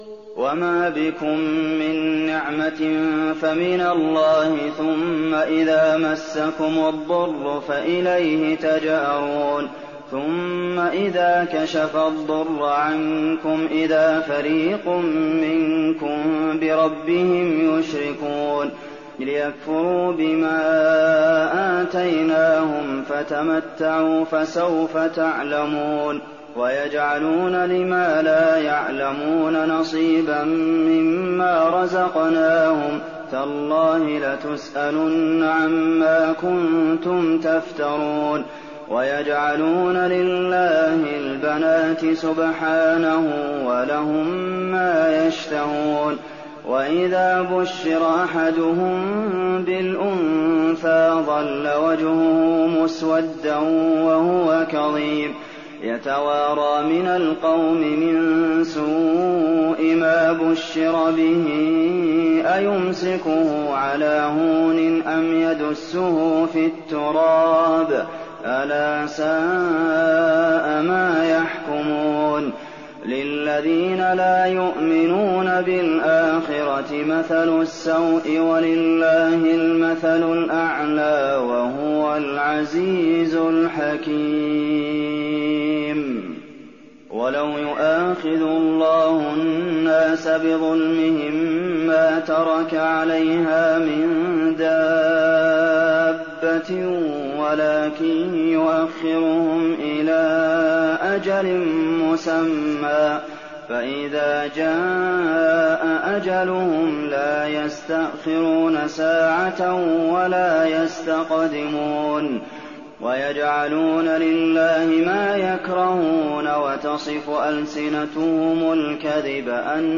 تراويح الليلة الرابعة عشر رمضان 1419هـ من سورة النحل (53-128) Taraweeh 14th night Ramadan 1419H from Surah An-Nahl > تراويح الحرم النبوي عام 1419 🕌 > التراويح - تلاوات الحرمين